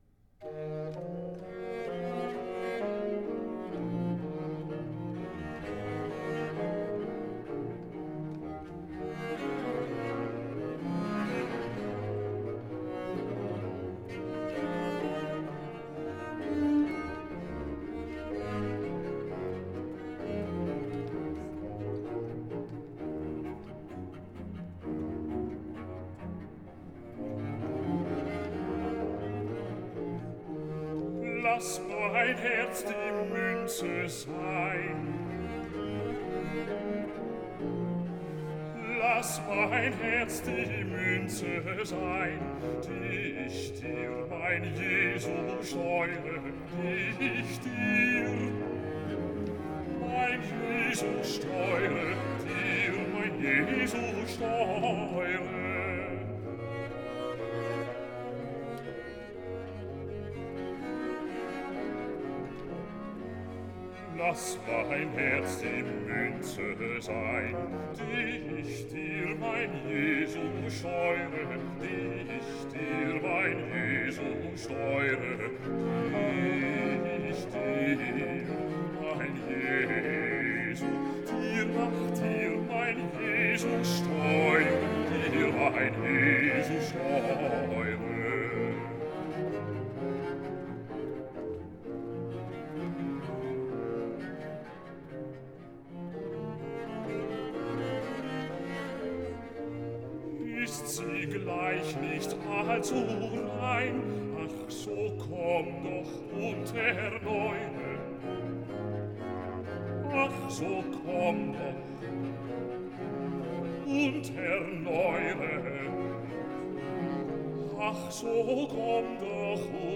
Aria - Basso